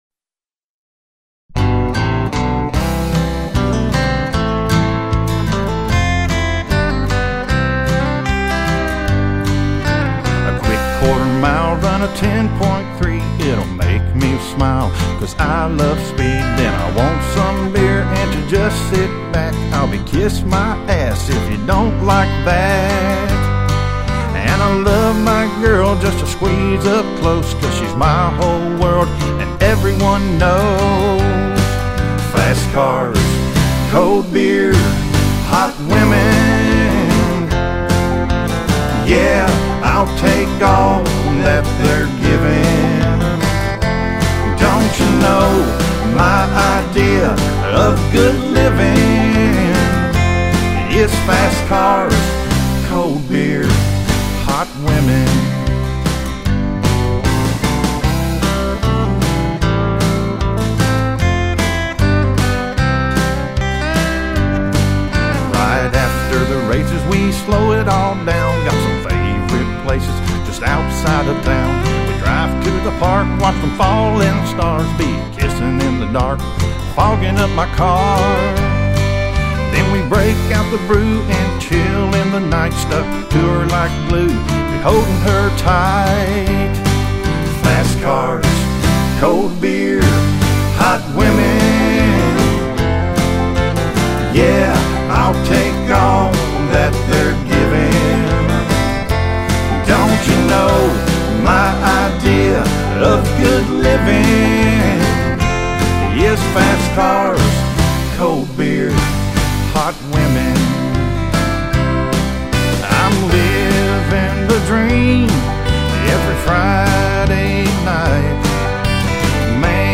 Complete Song
Complete Demo Song, with lyrics and music